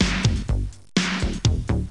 Synth Beat Sound Effect
Download a high-quality synth beat sound effect.
synth-beat.mp3